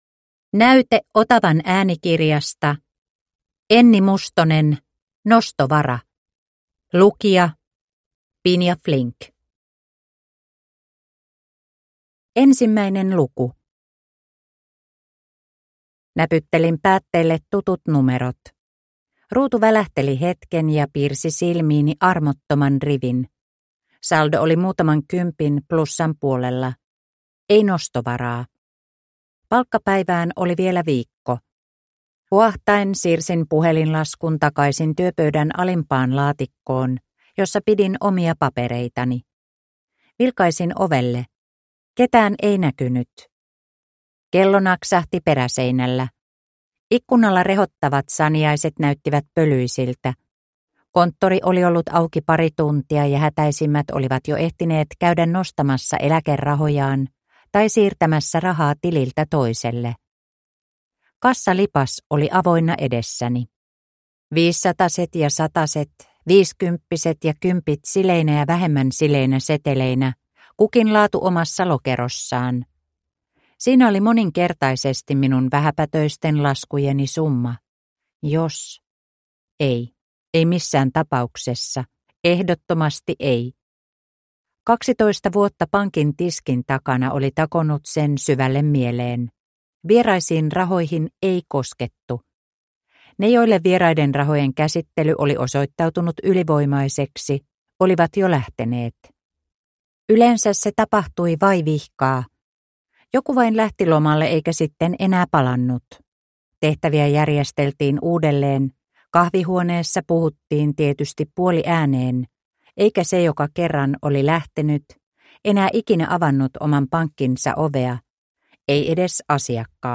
Nostovara – Ljudbok – Laddas ner